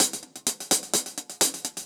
Index of /musicradar/ultimate-hihat-samples/128bpm
UHH_AcoustiHatC_128-04.wav